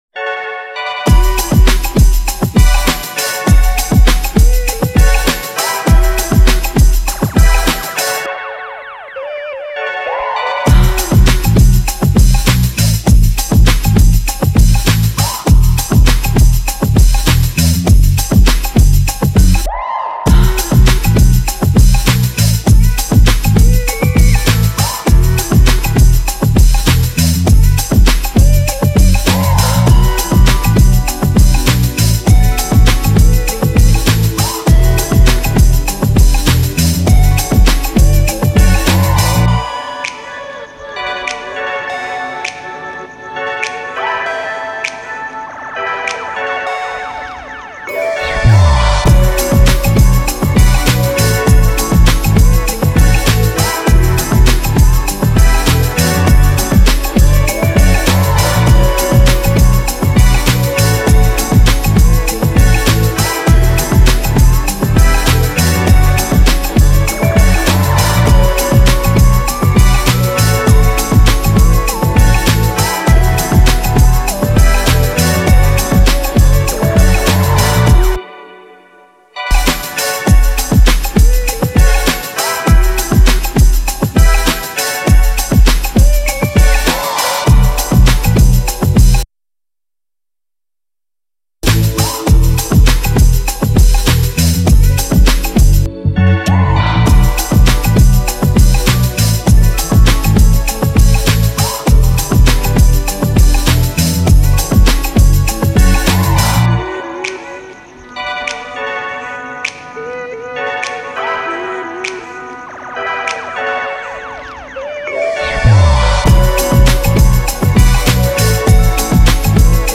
This is the official instrumental
Reggae Instrumentals